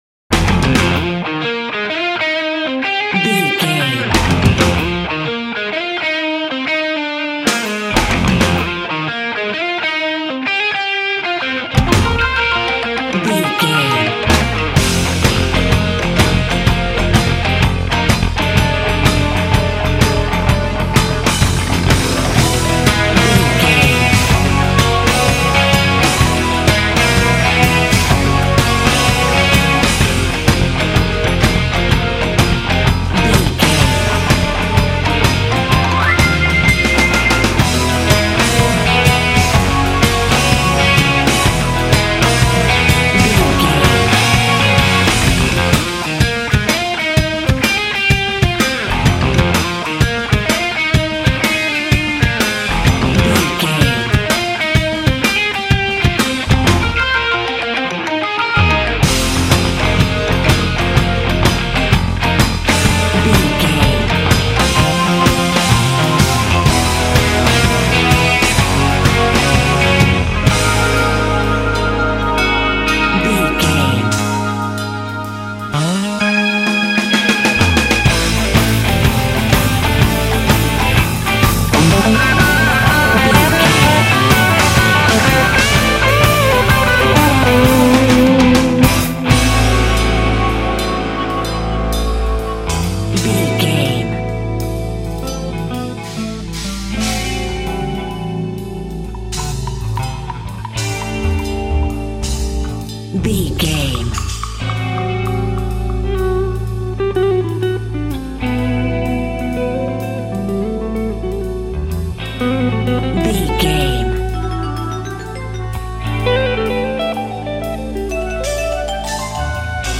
Ionian/Major
drums
electric guitar
bass guitar
hard rock
aggressive
energetic
intense
nu metal
alternative metal